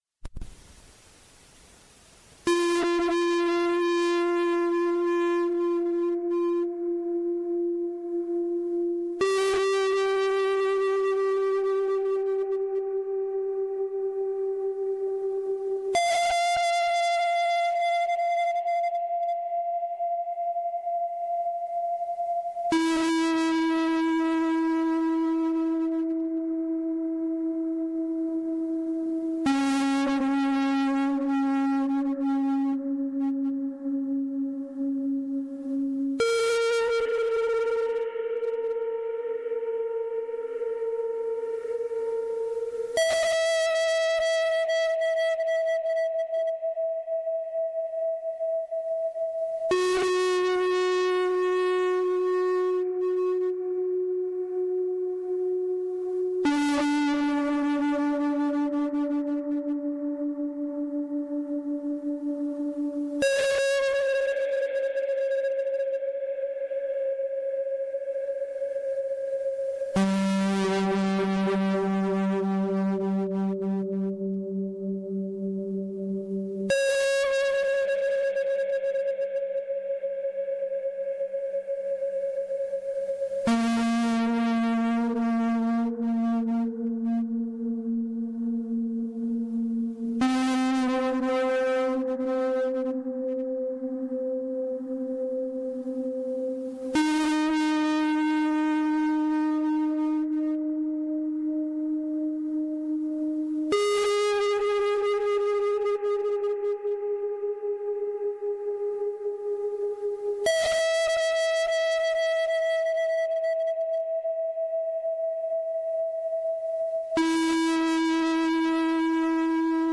Il software “F(n) := F(n-1) + F(n-2)”, crea una successione caotica di suoni generati seguendo la successione di Fibonacci (la successione di Fibonacci possiede moltissime proprietà di grande interesse.
Musica generativa